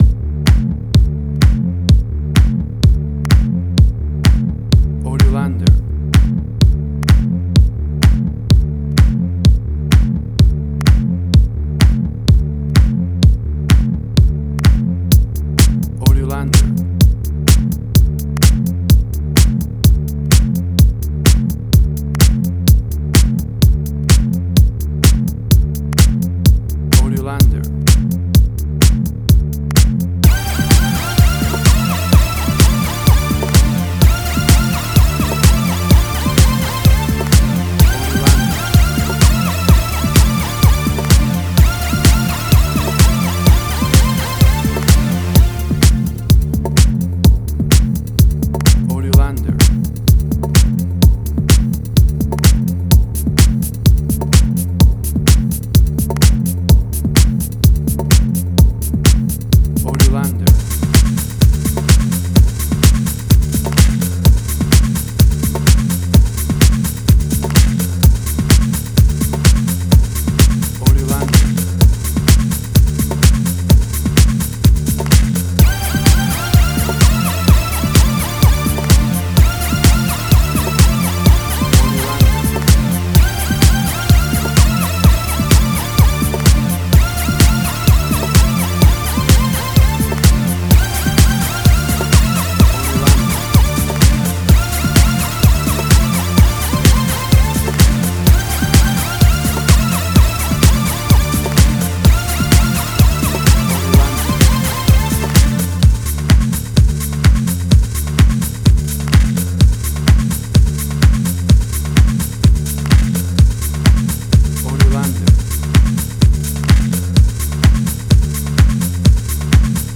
House.
Tempo (BPM): 127